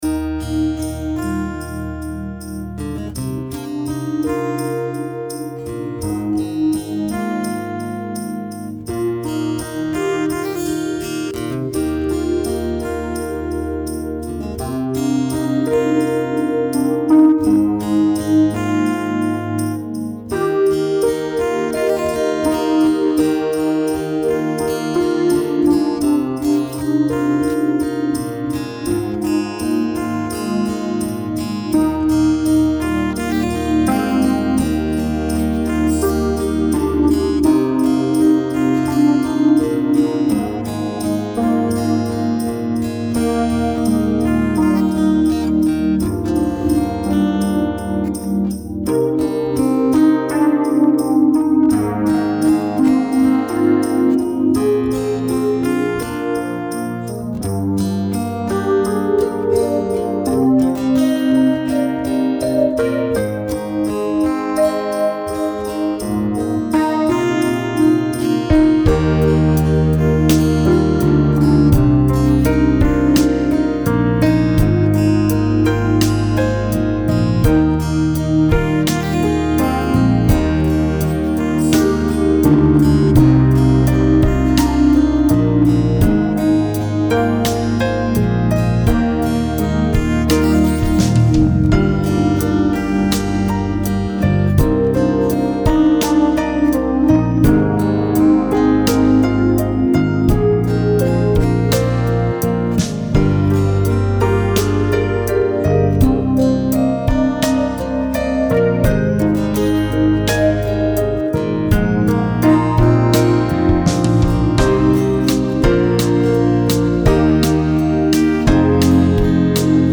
Playback zum Krippenspiel